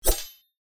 weapon audio
swordSwing1.ogg